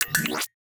Universal UI SFX / Clicks
UIClick_Equip Power Up Exit 03.wav